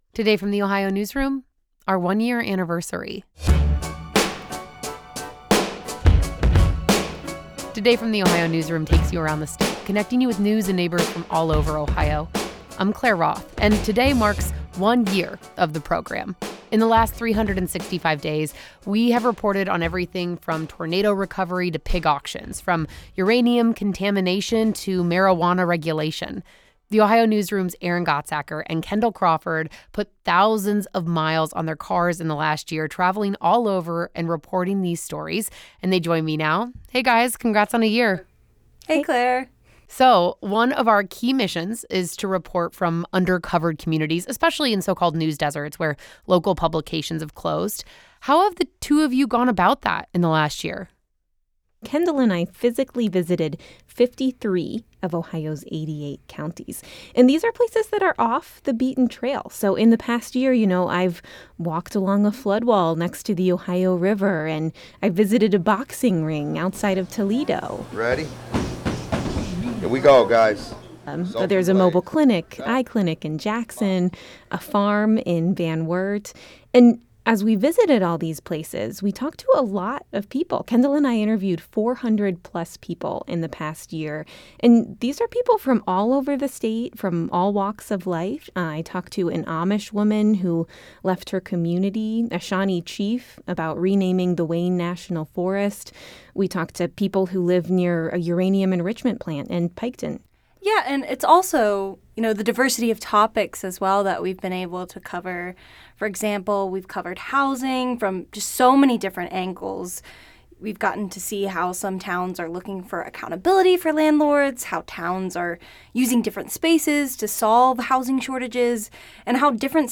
This conversation has been lightly edited for clarity and brevity